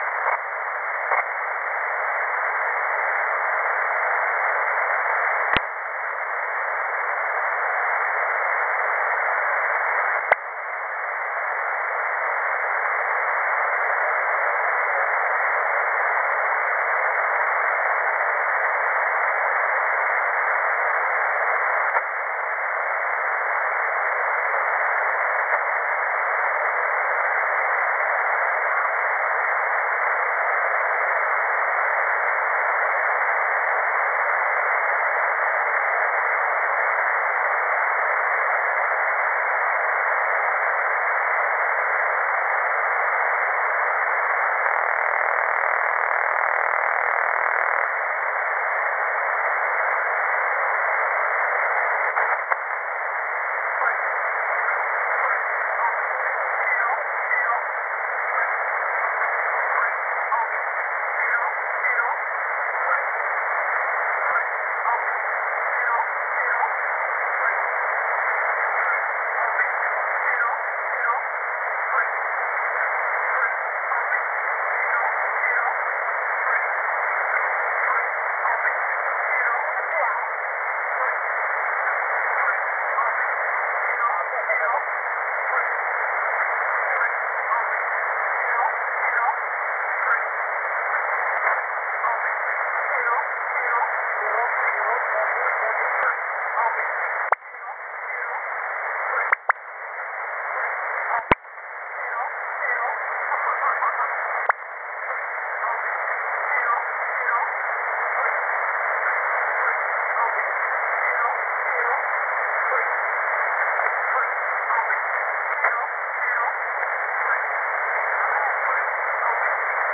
Time (UTC): 2005 Mode: USB Frequency: 11107 ID: 363 Group Count: 00 Recording: websdr_recording_2015-02-01T20-08-38Z_11107.0kHz.wav Comments: Some background noise on broadcast Date (mm/dd/yy): 01/02/15